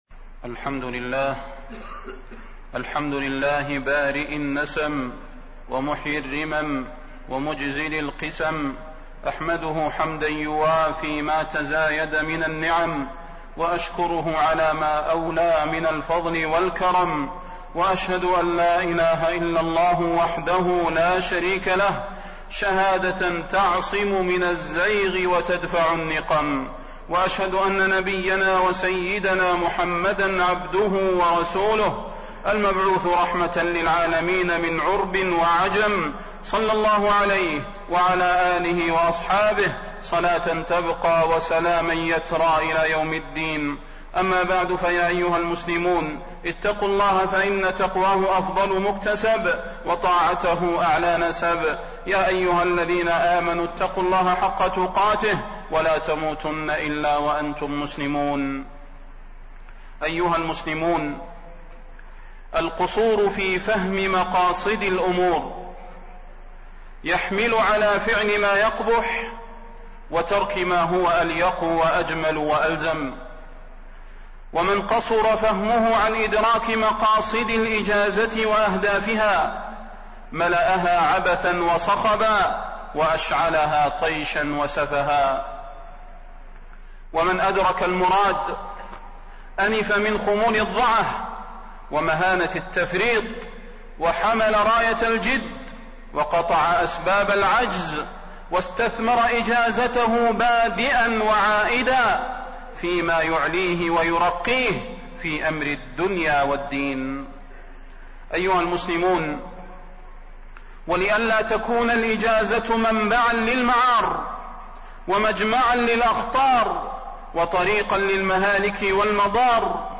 تاريخ النشر ٧ شعبان ١٤٣٢ هـ المكان: المسجد النبوي الشيخ: فضيلة الشيخ د. صلاح بن محمد البدير فضيلة الشيخ د. صلاح بن محمد البدير الأجازة ويقظة الأولياء